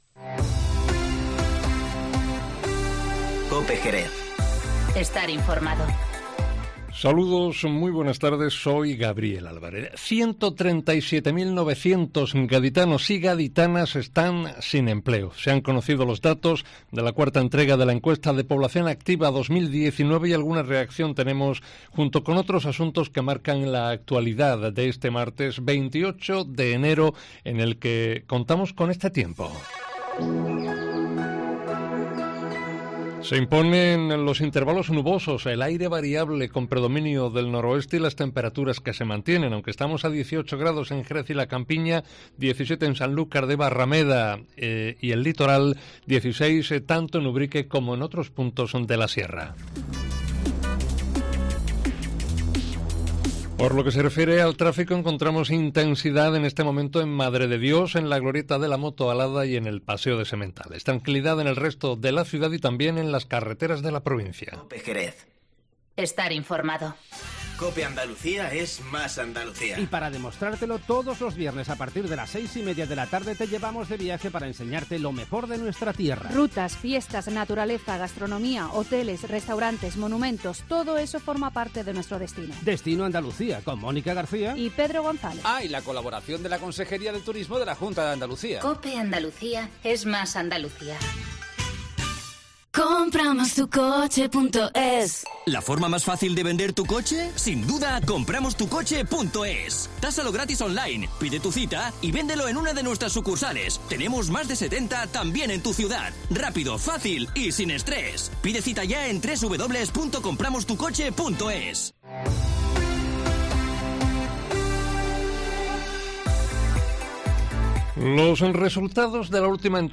Informativo Mediodía COPE en Jerez 28-01-20